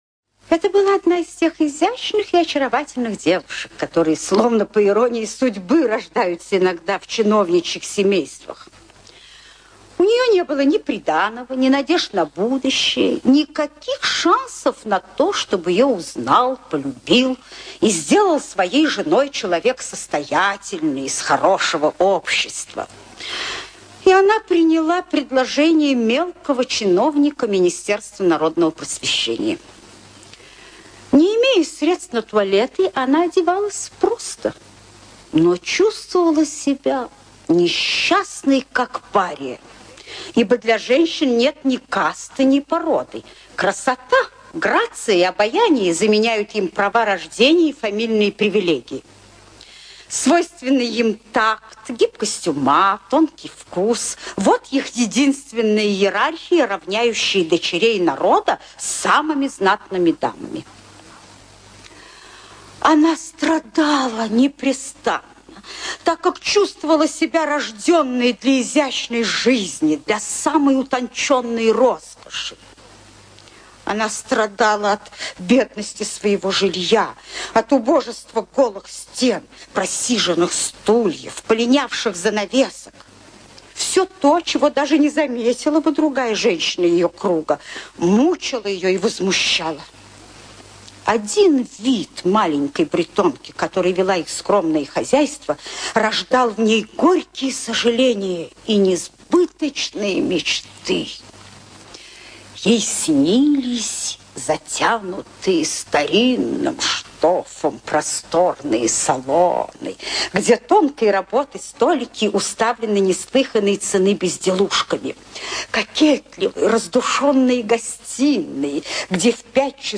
ЧитаетАндровская О.